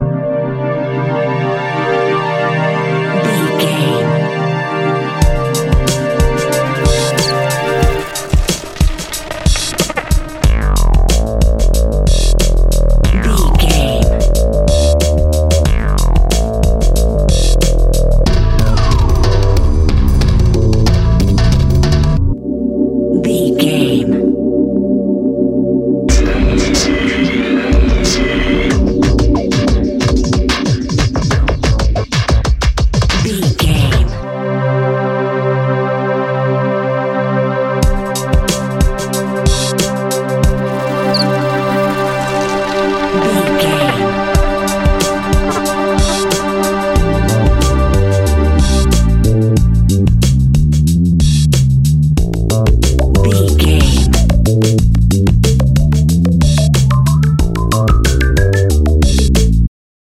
Aeolian/Minor
groovy
synthesiser
drums
hip hop
ambient
electronica